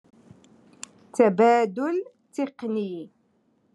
Moroccan Dialect- Rotation Three- Lesson Five